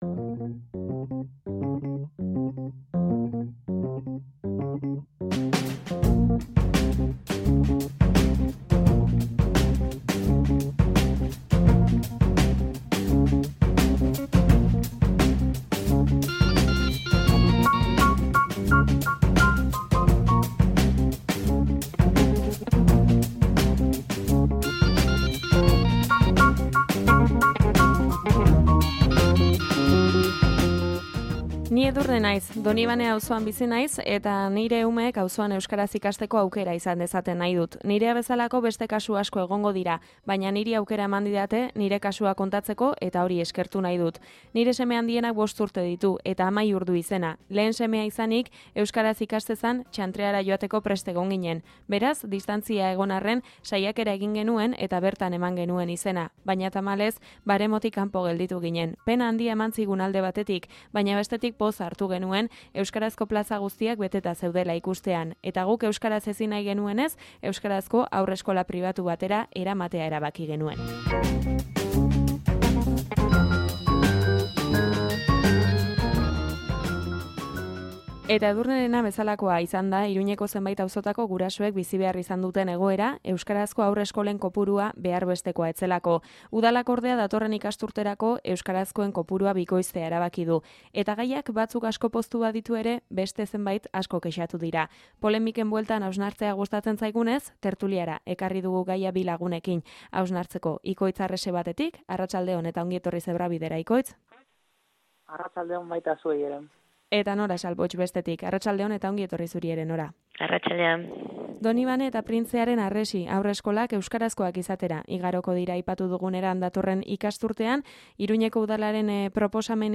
TERTULIA: Nafarroan euskarazko hezkuntza gatazkarako elementua al da?